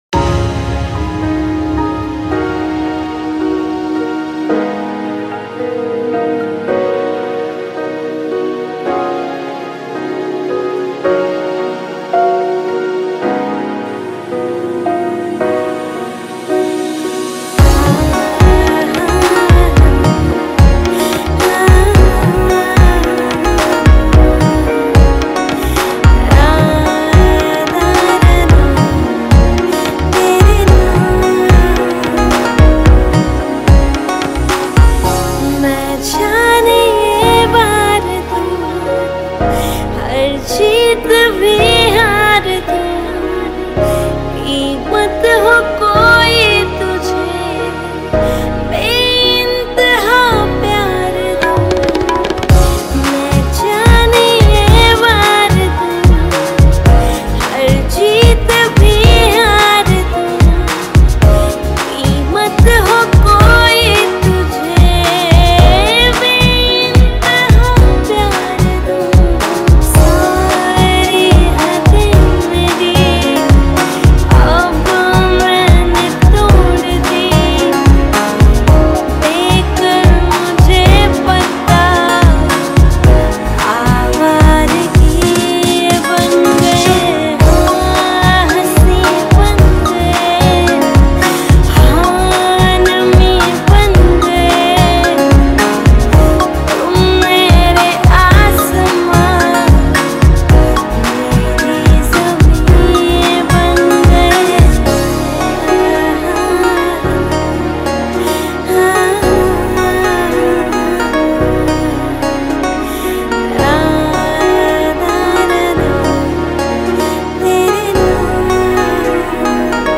DJ Remix
Single Mixes